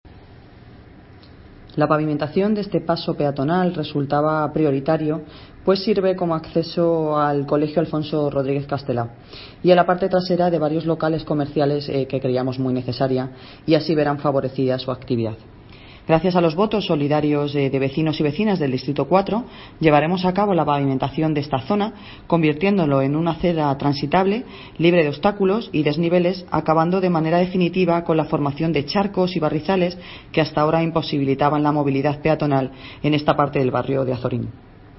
Audio - Noelia Posse (Concejal de Obras, Infraestructuras, Mantenimiento de vías públicas y Festejos)